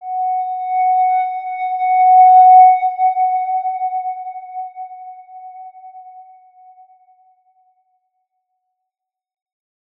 X_Windwistle-F#4-mf.wav